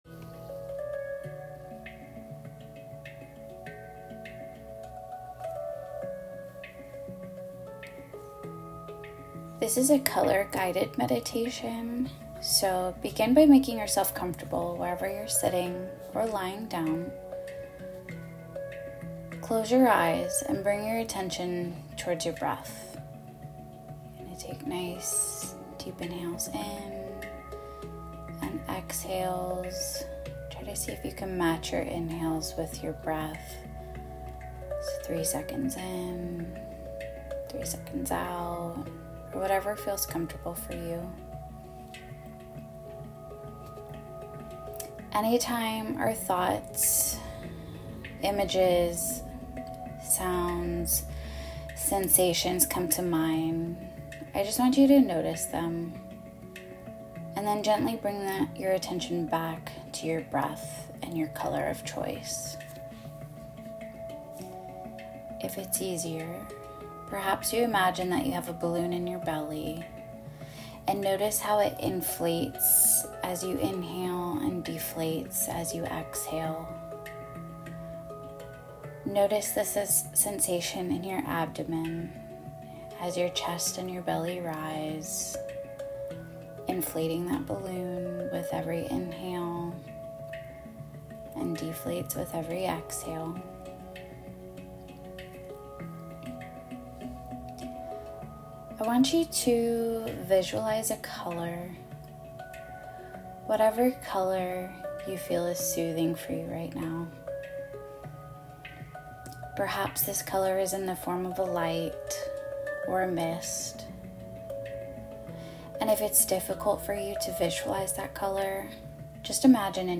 The Power of Color in this 5-minute Guided Visualization